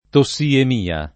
tossiemia [ to SS iem & a ]